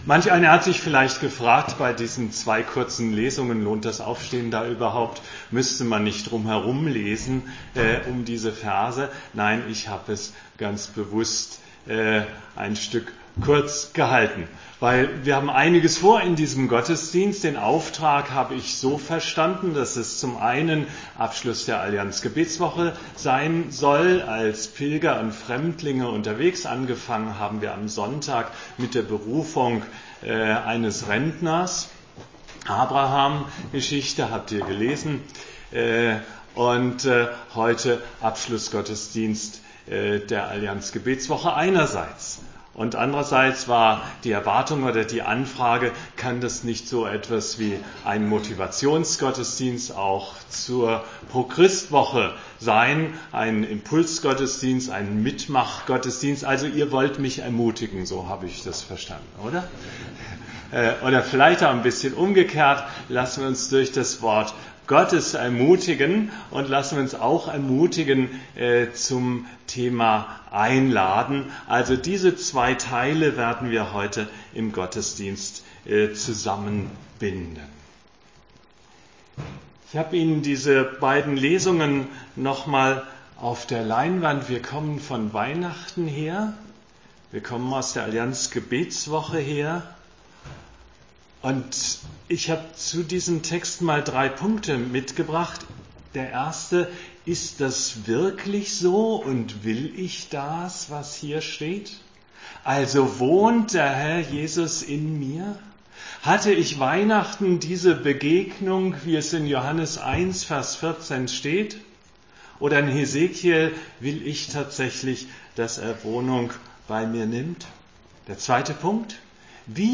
Dass die Predigt heute eine Ausnahme ist, hat gleich mehrere gute Gründe: wir feiern den Abschlussgottesdienst der Allianz-Gebetswoche und starten in das ProChrist-Jahr 2018.